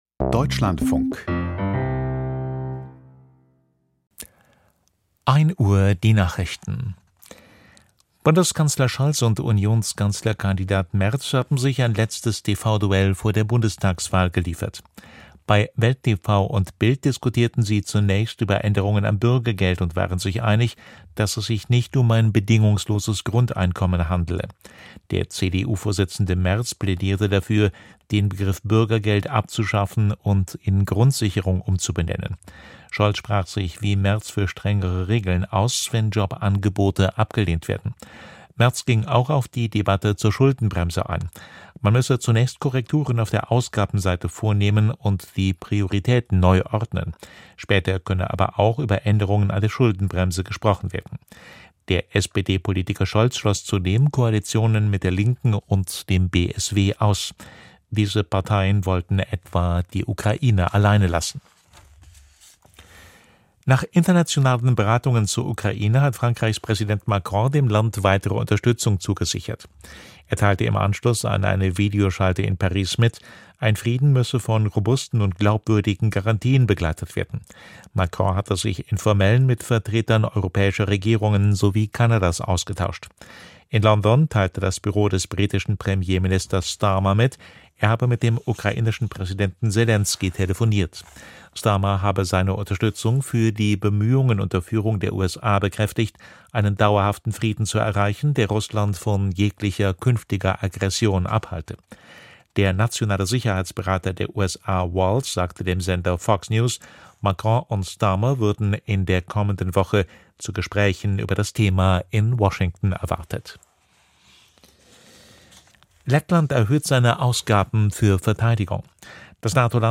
Die Deutschlandfunk-Nachrichten vom 31.12.2024, 06:30 Uhr.